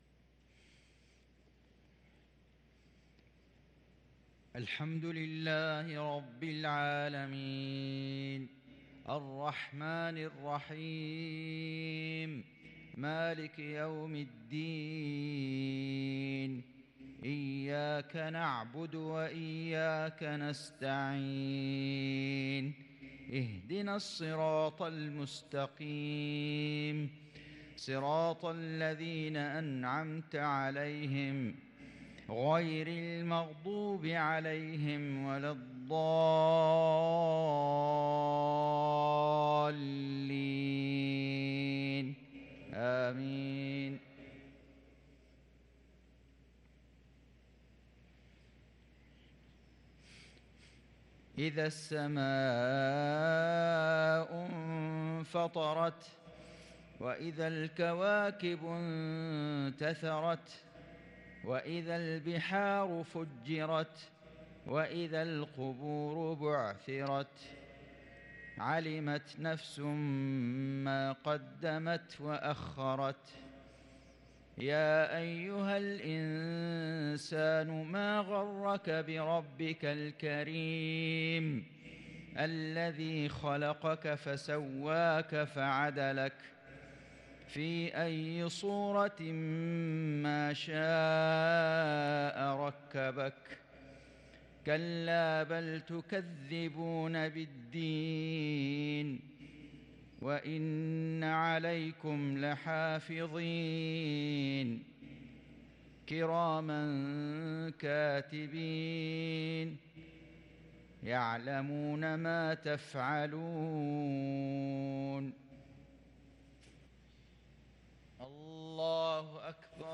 صلاة المغرب ٥ شوال ١٤٤٣هـ سورة الإنفطار | Maghrib prayer from Surah al-Infitar 6-5-2022 > 1443 🕋 > الفروض - تلاوات الحرمين